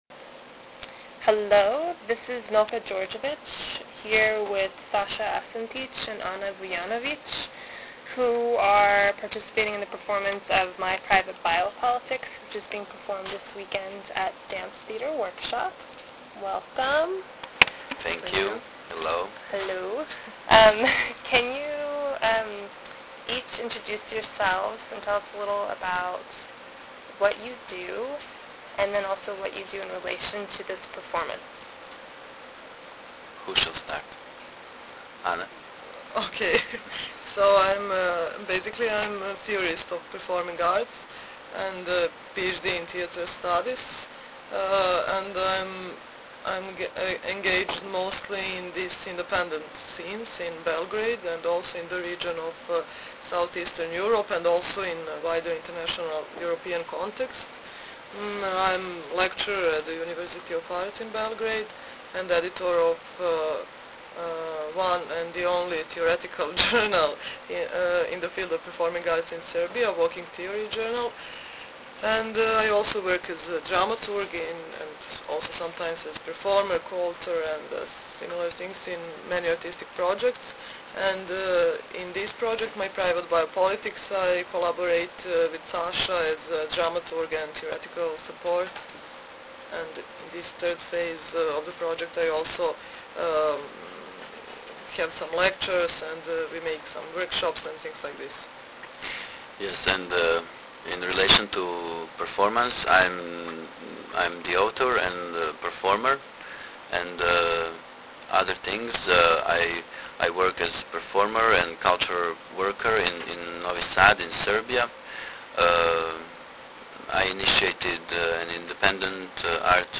My private bio-politics Listen to this interview